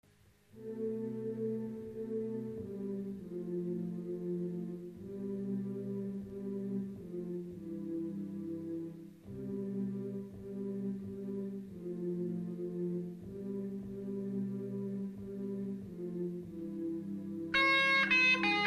Запись крайне трушная...
keyboard, percussions, vocal, backvocal.
guitar, vocal, backvocal.